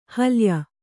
♪ alya